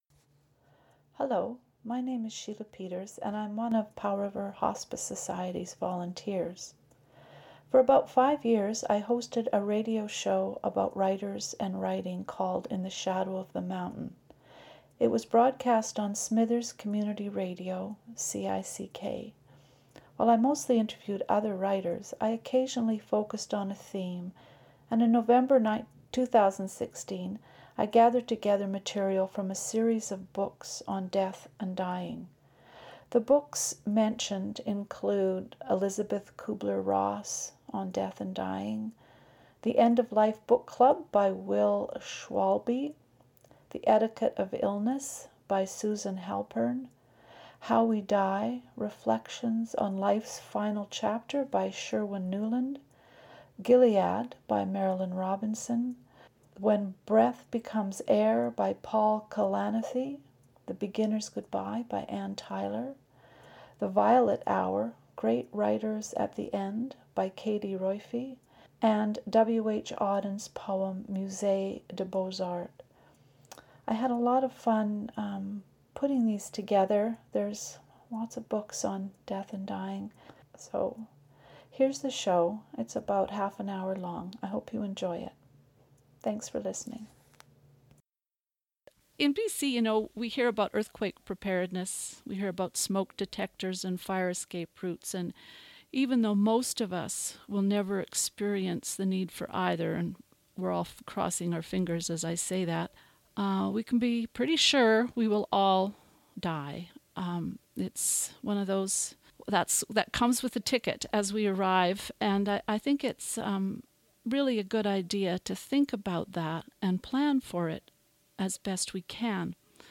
In November 2016, I created a show about books that in some way talk about death and dying. This is an abridged version of that show.
death-and-dying-radio-show-for-pr-hospice.mp3